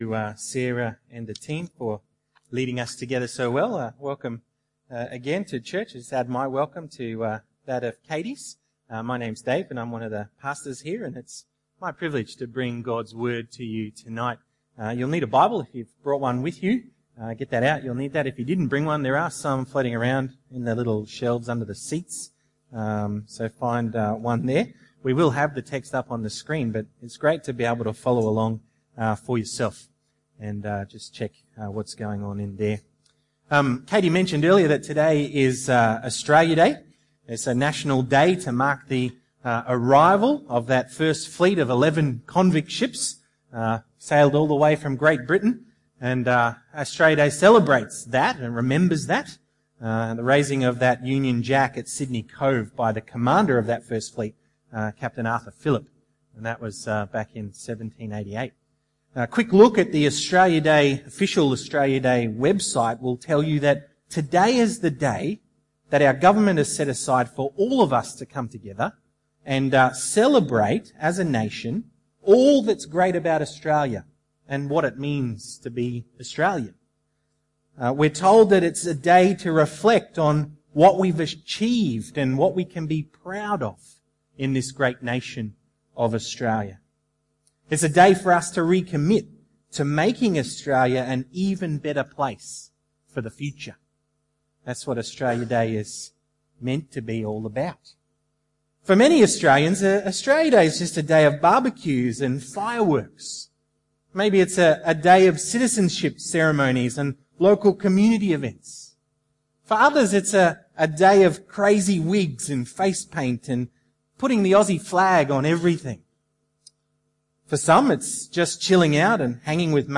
Individual Message
Philippians 3:17-4:1 Tagged with Sunday Evening